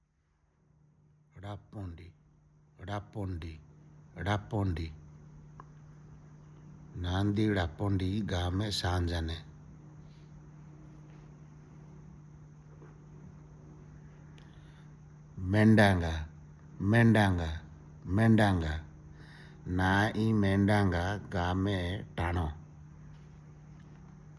Elicitation of words about human body parts